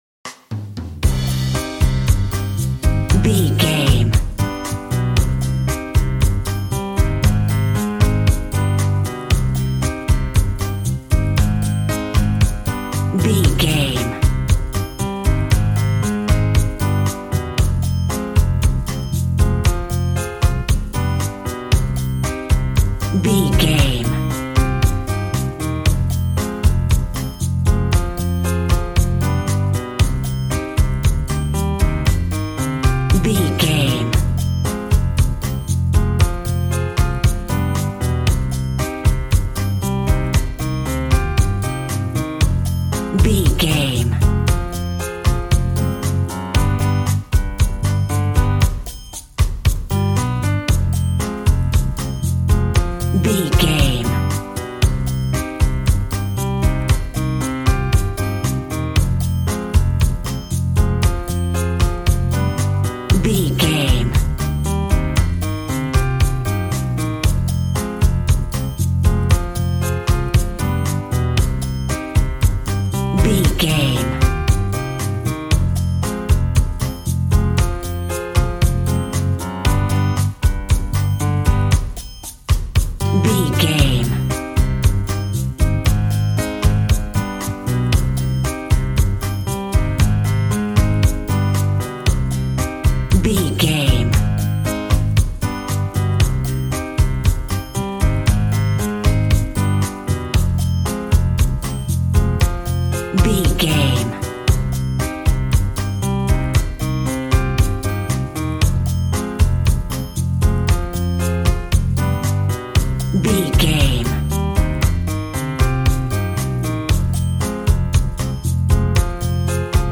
An exotic and colorful piece of Espanic and Latin music.
Aeolian/Minor
funky
energetic
romantic
percussion
electric guitar
acoustic guitar